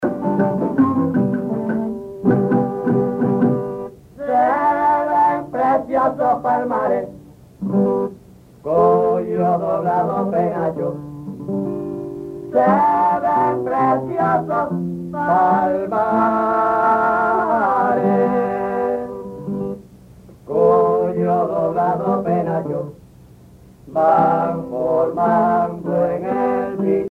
Parranda
Pièces musicales tirées de la Parranda Tipica Espirituana, Sancti Spiritus, Cuba
Pièce musicale inédite